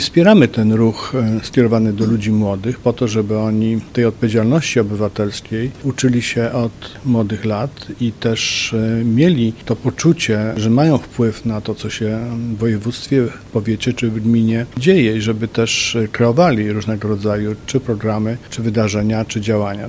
Marszałek województwa mazowieckiego, Adam Struzik mówi, że ważne jest, aby od młodych lat młodzież uczyła się obowiązkowości obywatelskiej.